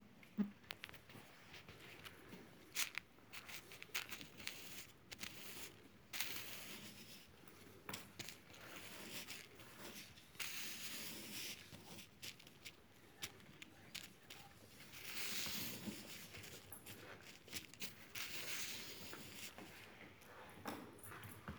Alcuni scatti e due audio ambientali registrati durante la performance.